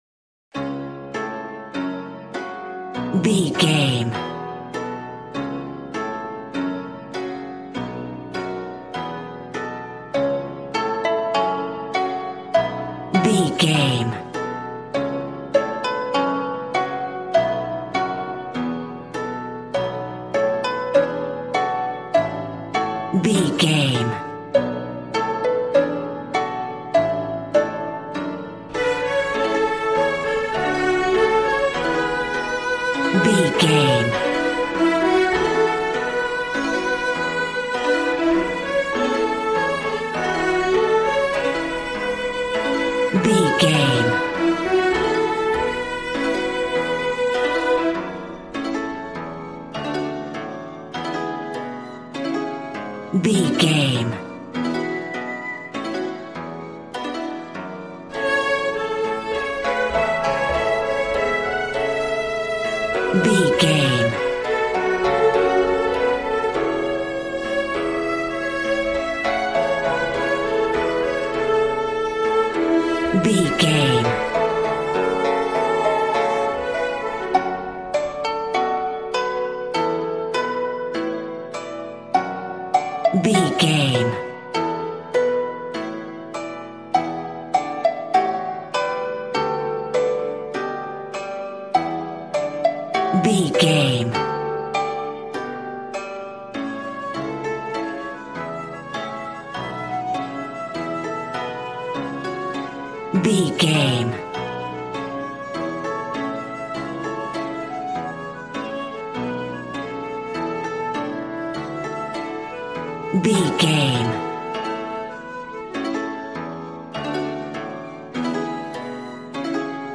Ionian/Major
happy
bouncy
conga